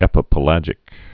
(ĕpə-pə-lăjĭk)